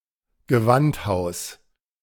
Gewandhaus (German: [ɡəˈvanthaʊs]